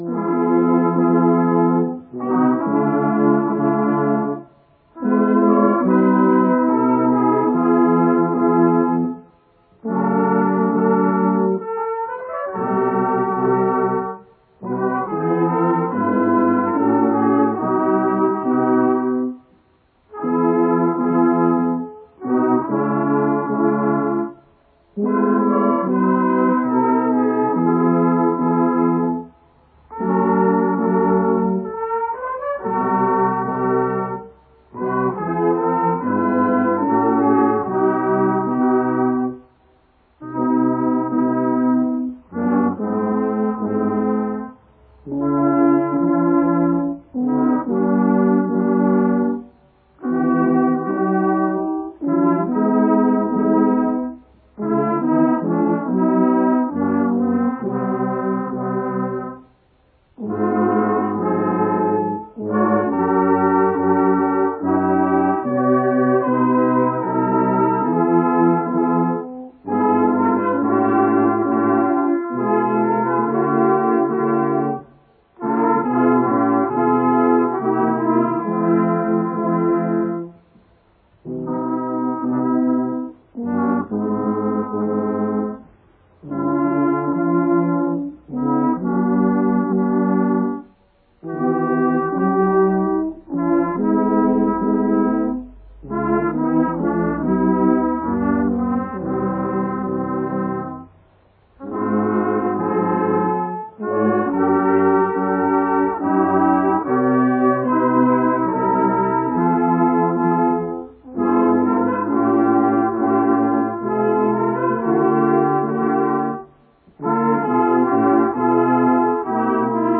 Volkstrauertag auf dem Goldkronacher Friedhof Volkstrauertrag am Denkmal in Brandholz
Volkstrauertag-Gok.mp3